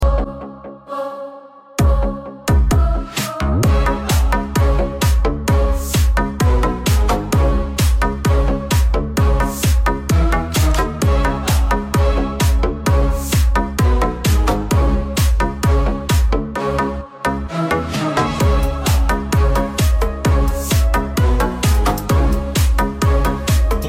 Chinese electric car sound effects free download